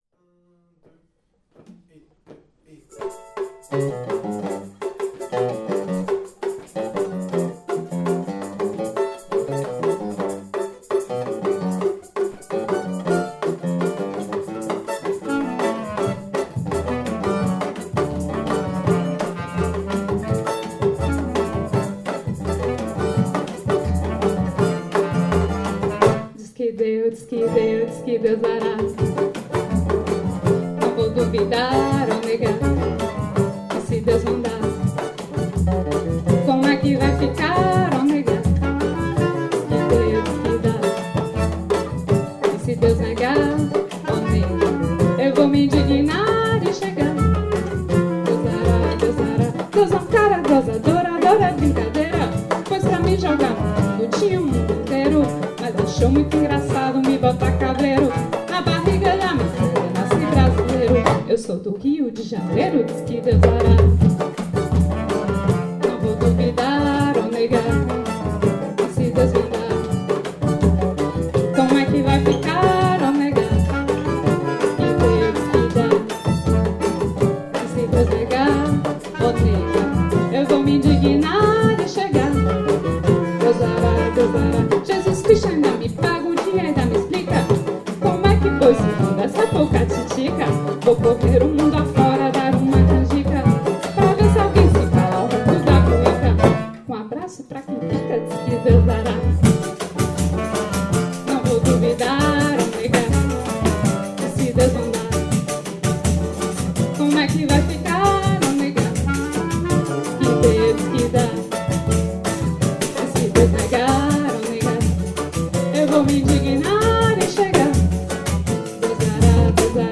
Partido Alto
Rec atelier